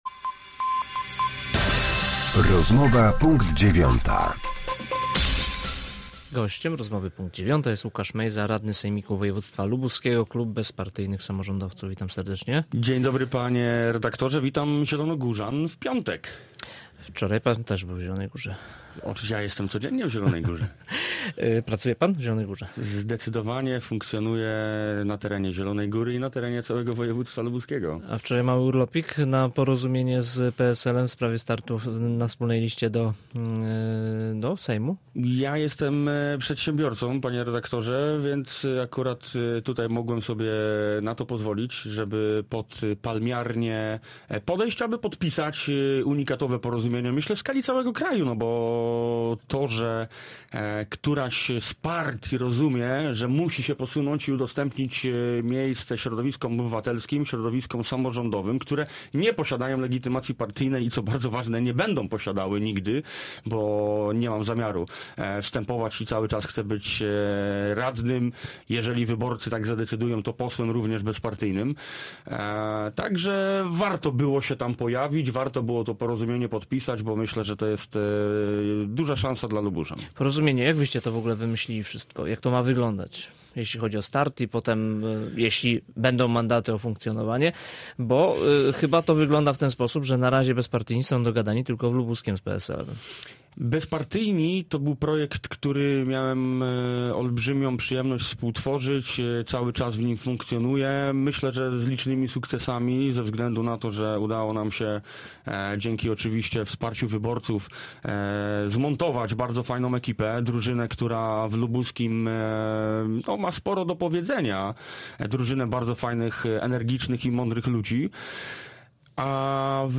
Z radnym klubu Bezpartyjni Samorządowcy w sejmiku województwa lubuskiego rozmawiał